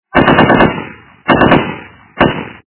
Автомат - Очередь Звук Звуки Автоматна - черга